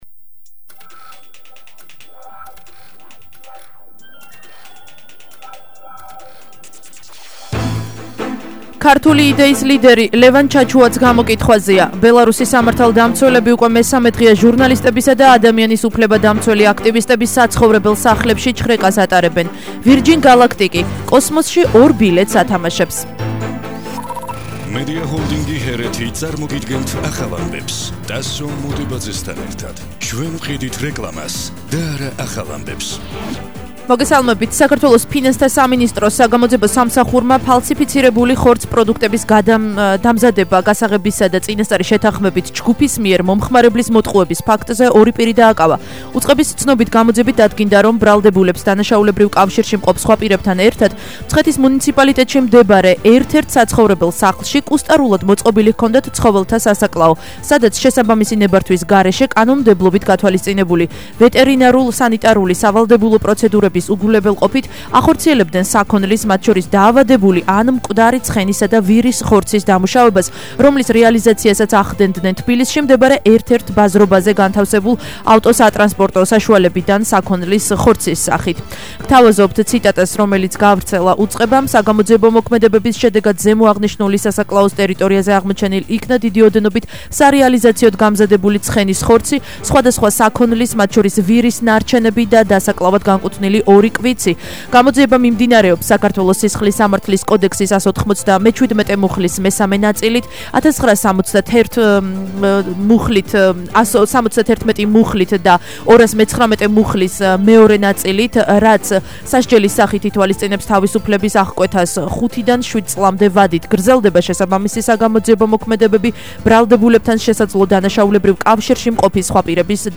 ახალი ამბები 16:00 საათზე –16/07/21 - HeretiFM